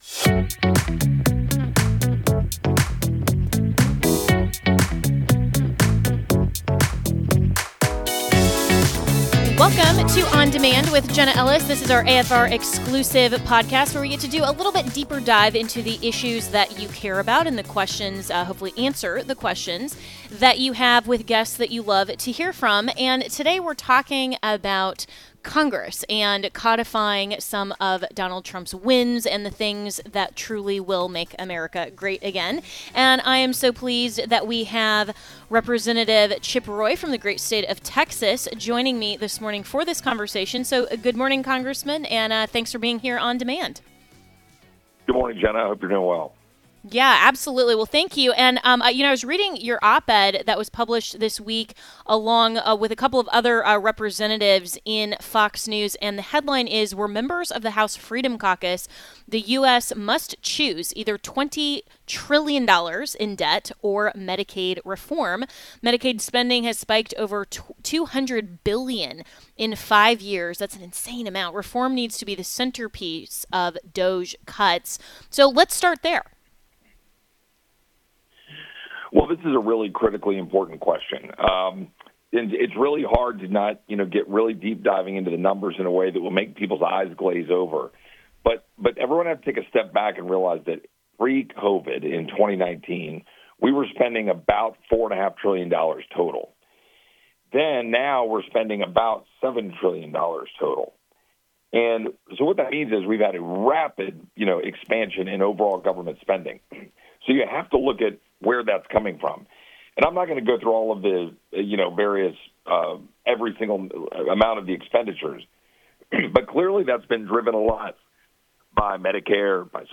Rep. Chip Roy joins the podcast to discuss the CR and Trump's Agenda